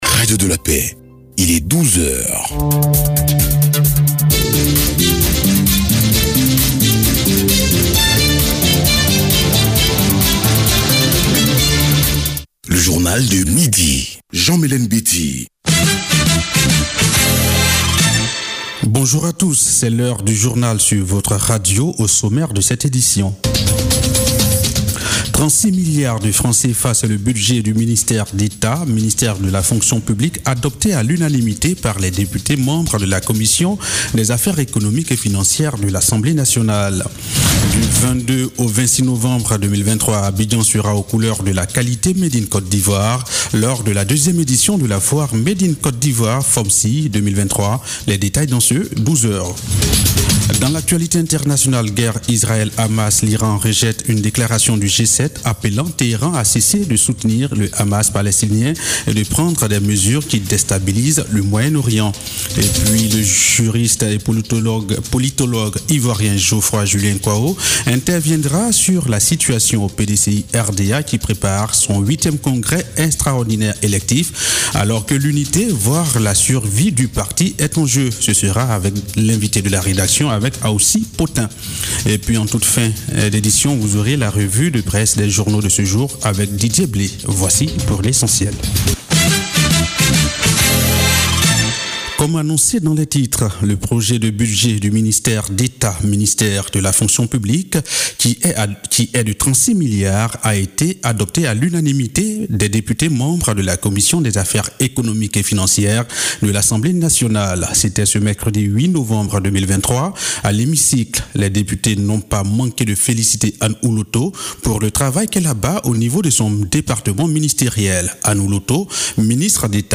Journal de 12H – 9 novembre 2023 - Site Officiel de Radio de la Paix